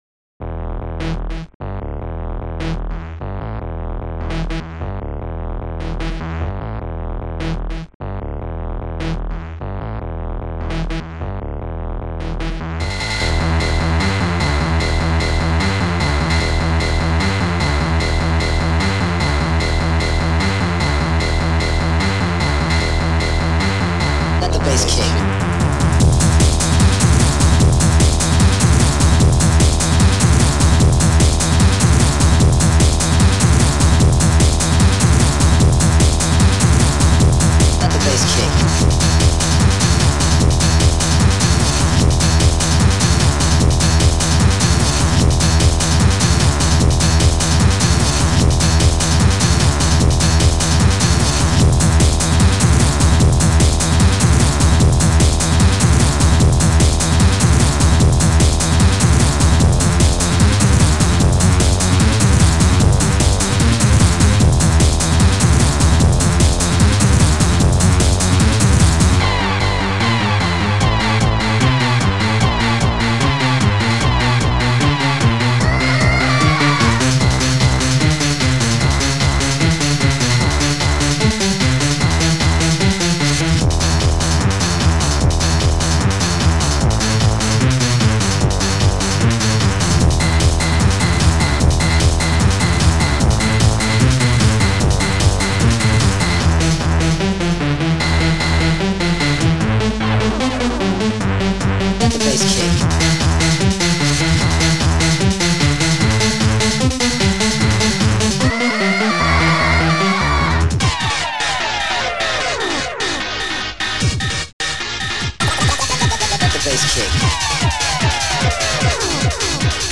Protracker and family
150--b-p-m--r-u-s-h-!
sirene